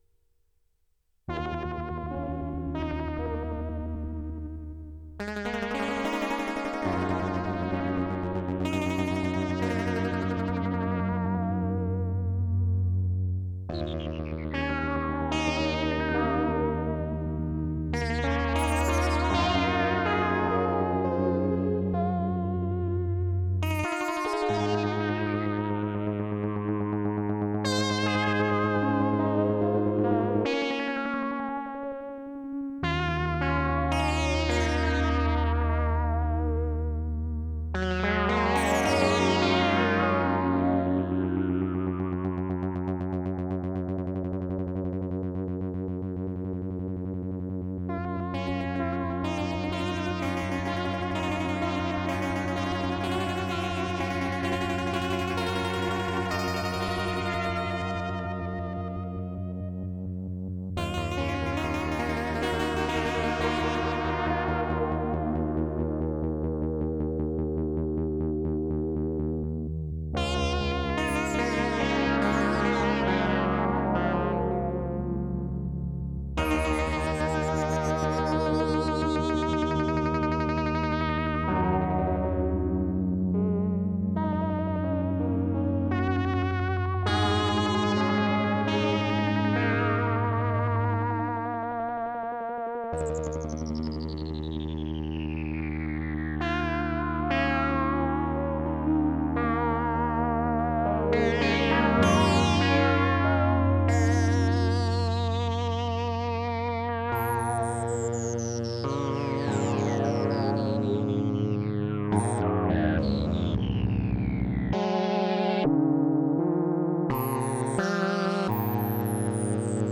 E: Same patch with some light tweaks took me in a new direction. Changes up a bit throughout.